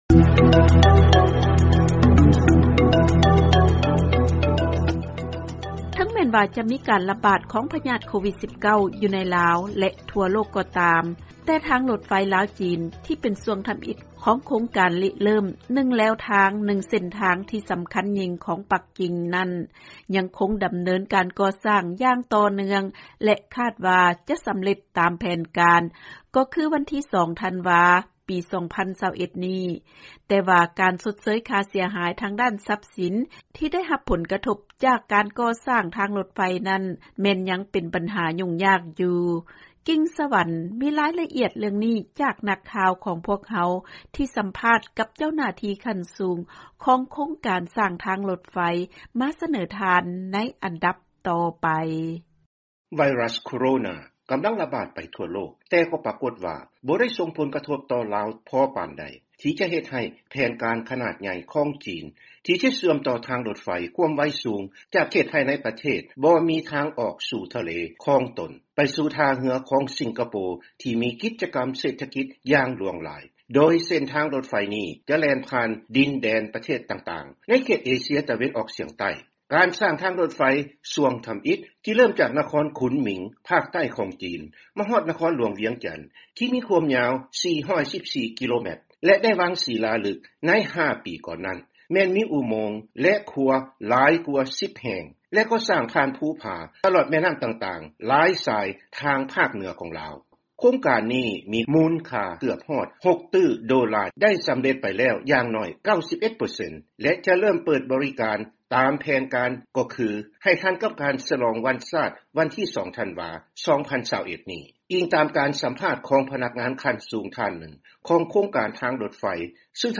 ເຊີນຟັງລາຍງານລົດໄຟຄວາມໄວສູງ ຈາກ ສປປ ລາວ